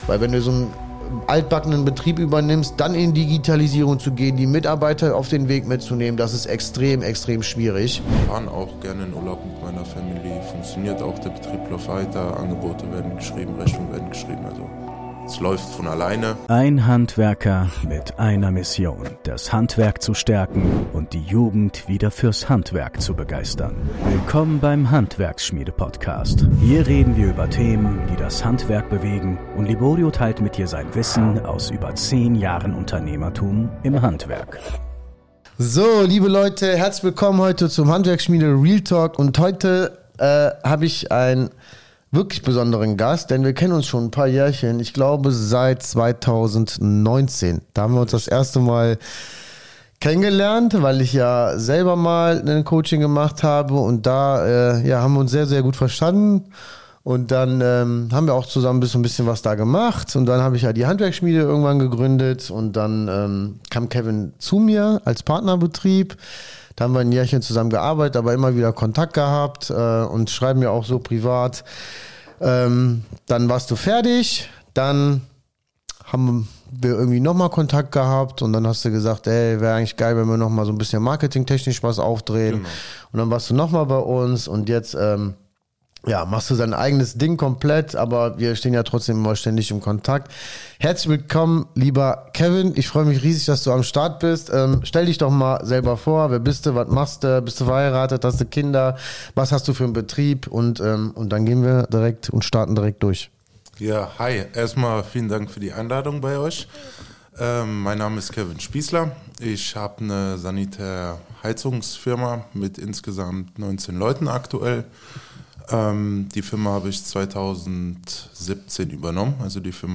Wie du einen traditionellen SHK-Betrieb übernimmst und digitalisierst | Interview